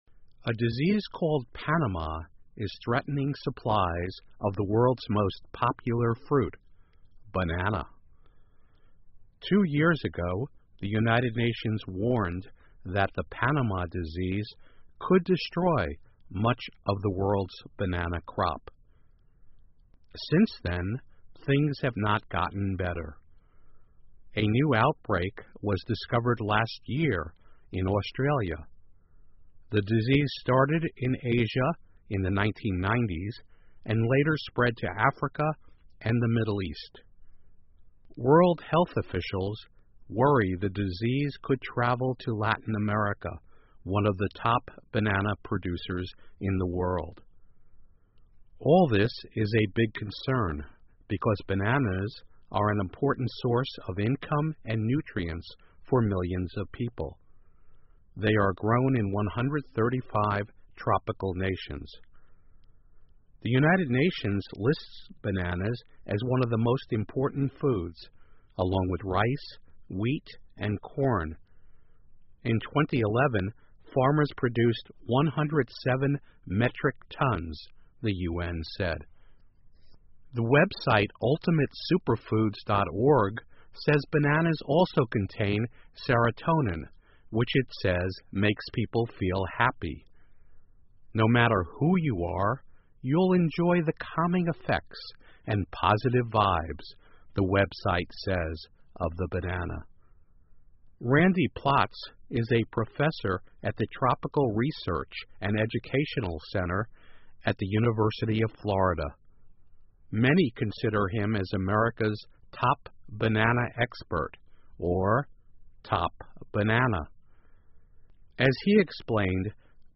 VOA慢速英语2016--疾病威胁世界香蕉供应 听力文件下载—在线英语听力室